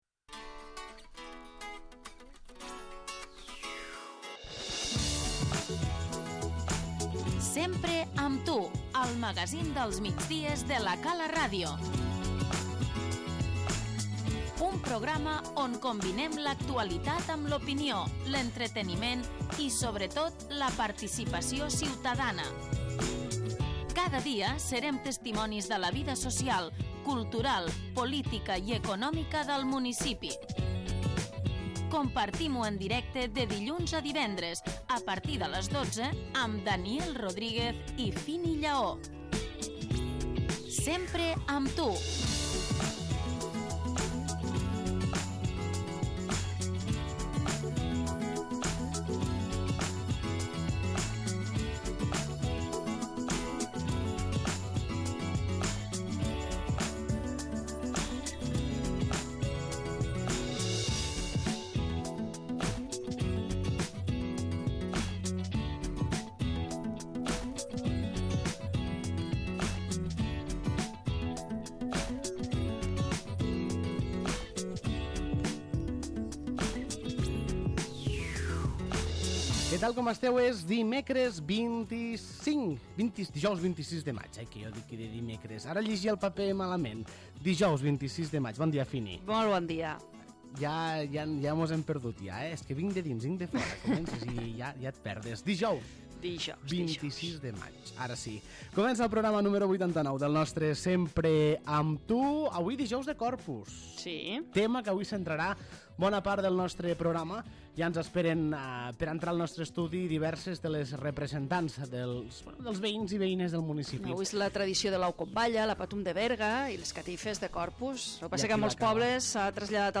L'ENTREVISTA Aquest cap de setmana tindrà lloc la 32a edició de les Catifes de Corpus a la població.